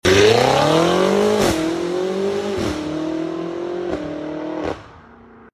The BMW M5 Competition.